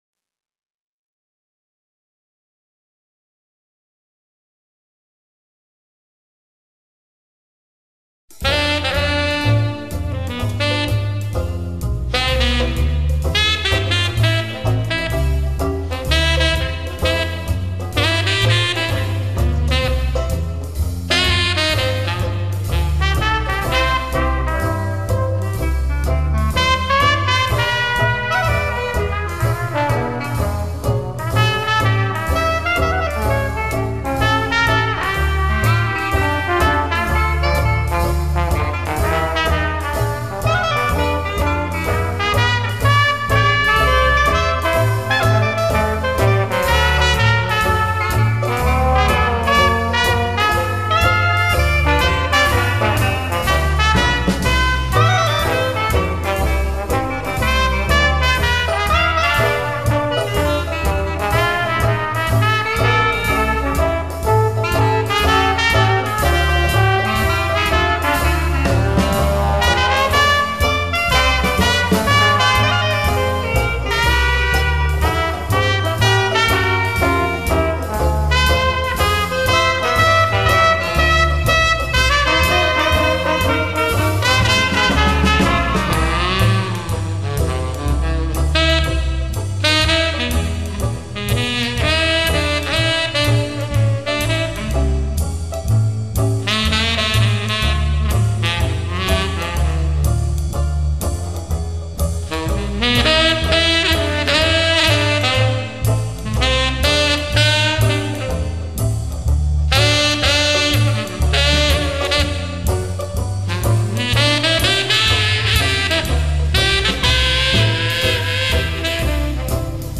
4wHYfALLOFMEJazzNEWORLEANS3.mp3